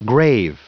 Prononciation du mot grave en anglais (fichier audio)
Prononciation du mot : grave